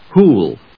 /húːl(米国英語), hu:l(英国英語)/